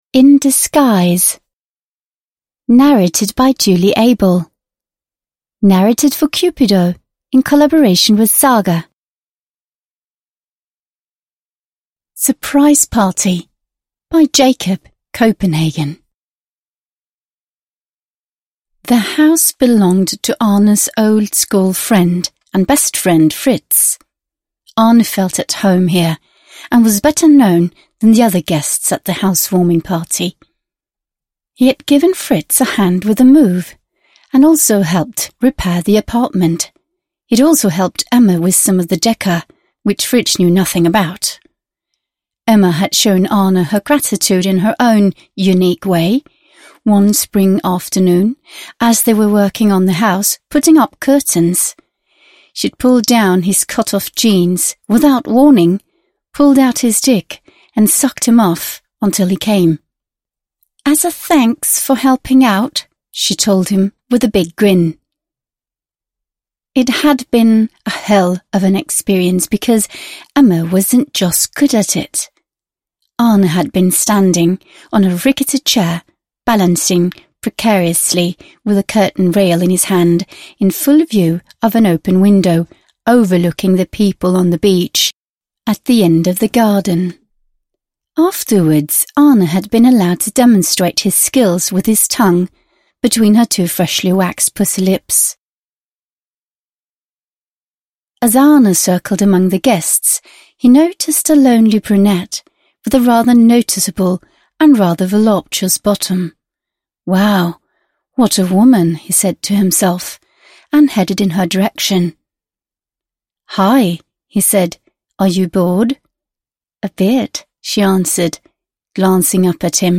In Disguise (ljudbok) av Cupido